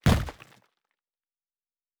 Fantasy Interface Sounds
Stone 10.wav